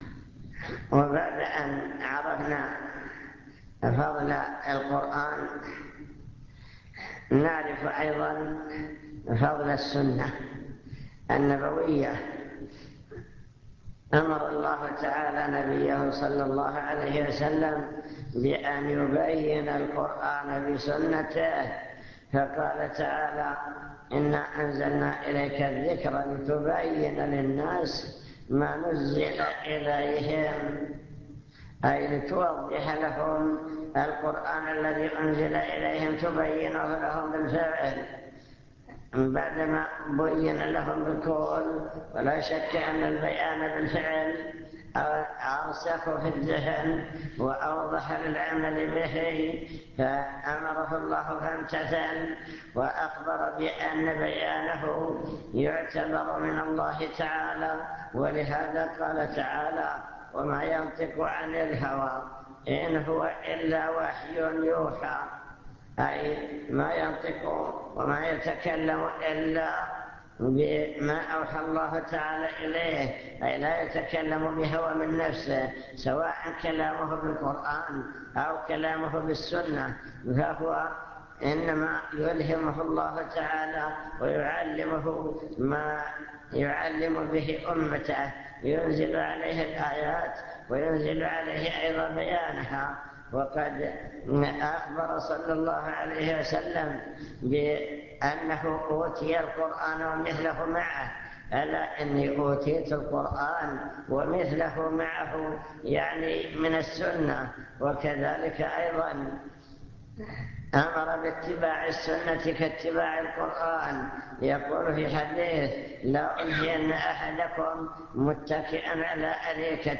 المكتبة الصوتية  تسجيلات - محاضرات ودروس  محاضرة عن القرآن والسنة